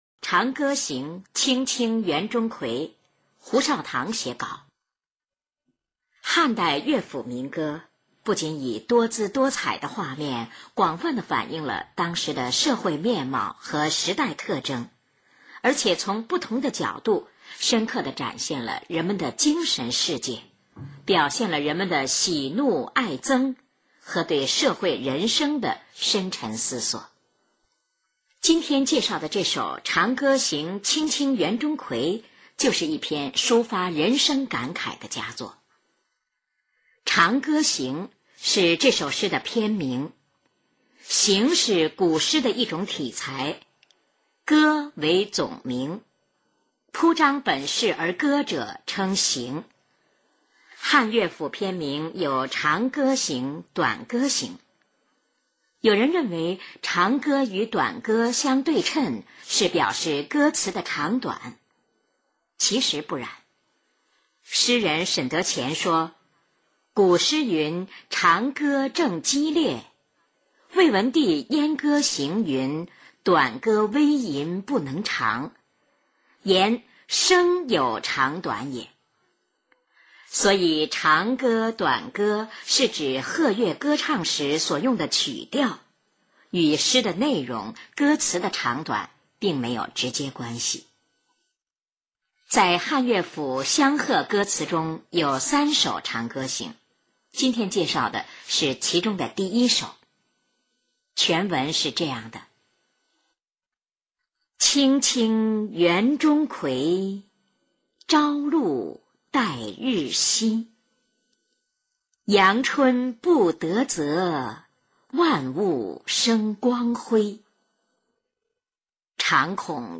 印象最深的是后来人们概括的“三名”：名人介绍名作，由名播音员广播。